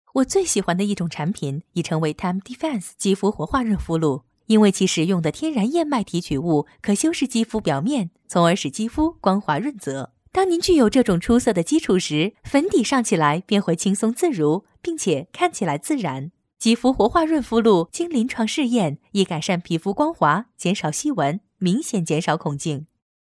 • 7央视女声1号
品牌广告-大气自然